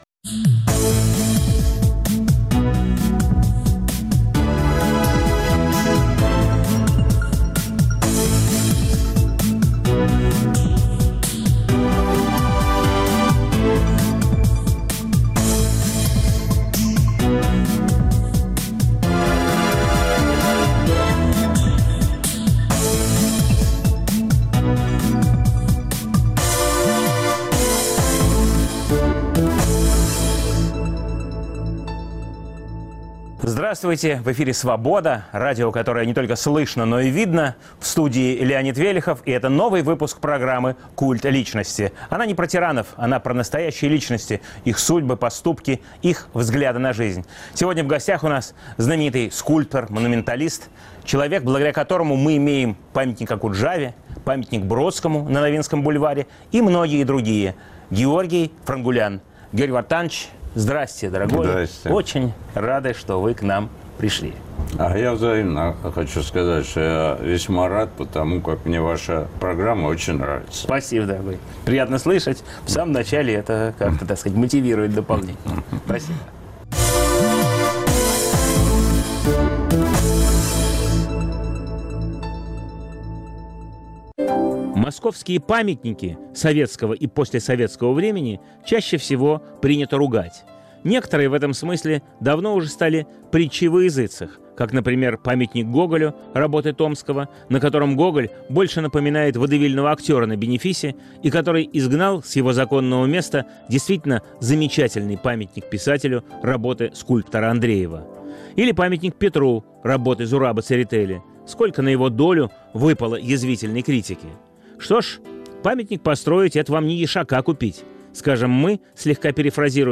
Новый выпуск программы о настоящих личностях, их судьбах, поступках и взглядах на жизнь. В студии скульптор, автор памятников Булату Окуджаве и Иосифу Бродскому, Георгий Франгулян.